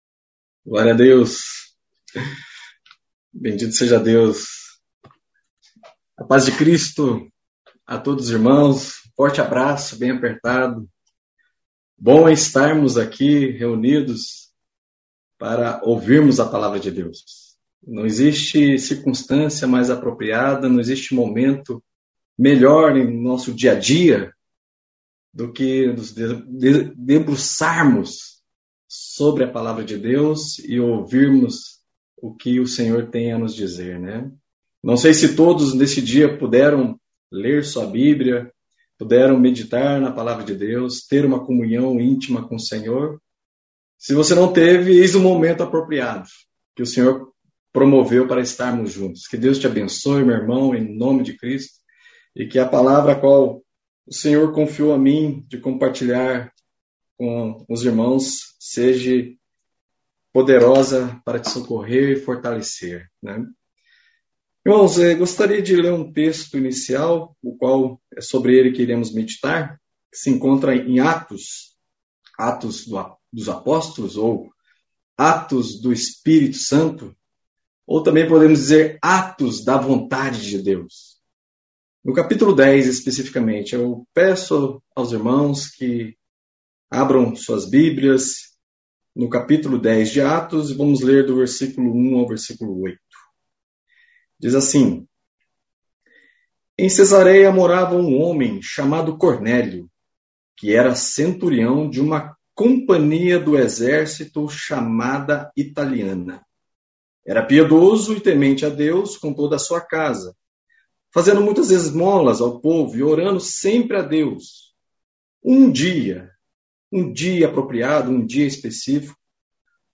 Mensagem compartilhada
na reunião de ensino em Curitiba através do aplicativo